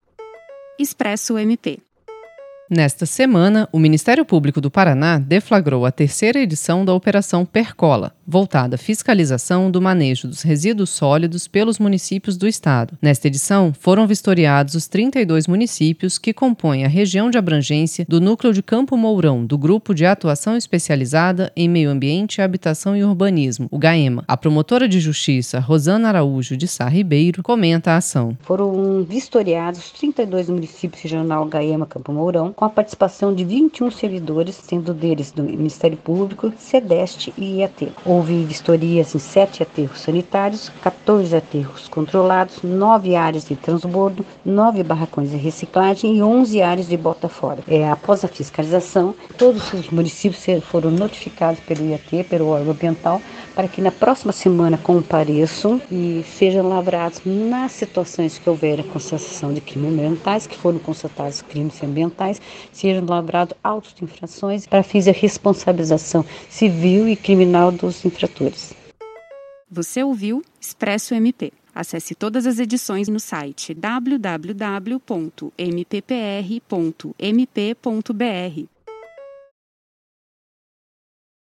Ouça abaixo comentário da promotora de justiça Rosana Araujo de Sá Ribeiro sobre os resultados da Operação Percola III